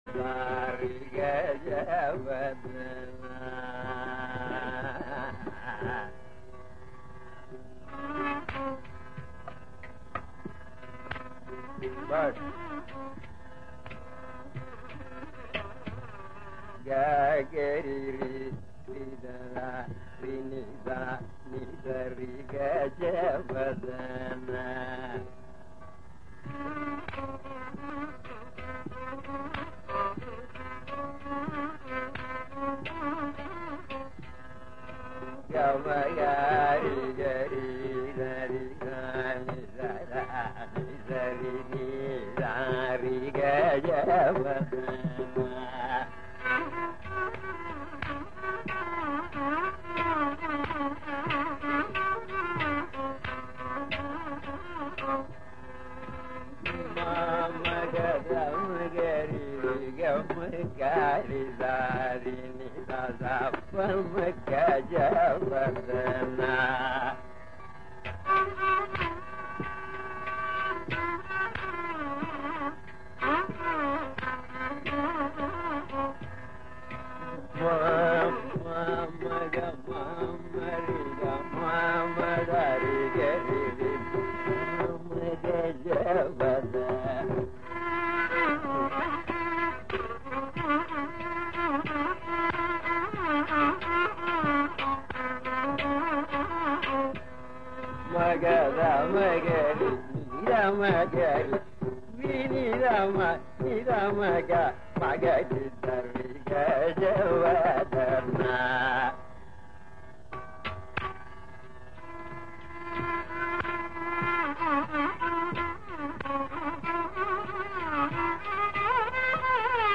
06c-gajavadana- thodi-swarakalpana-ssi-c15.mp3